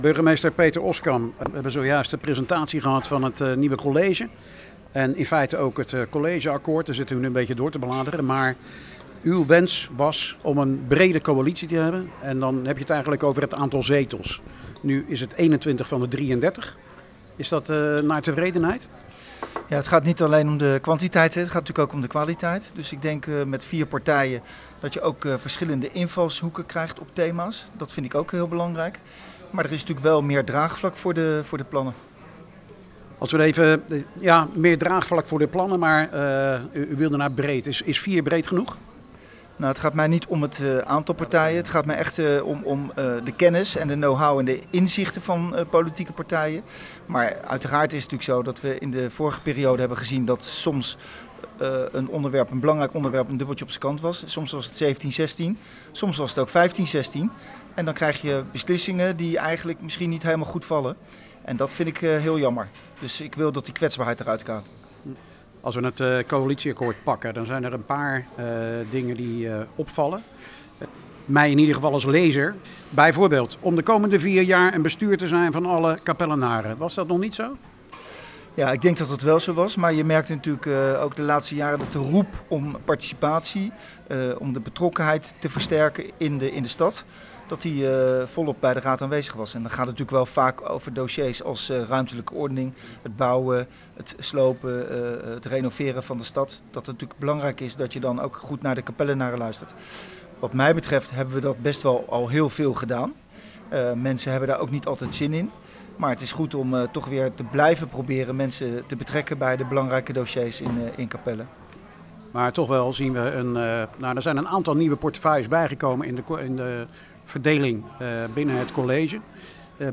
sprak met burgemeester Peter Oskam over dat college en het coalitieakkoord. Aan de orde komt dat brede college, burgerparticipatie, de wens vanuit de politiek om wijkagenten die Oskam niet ziet gebeuren, opvang van vluchtelingen en meer.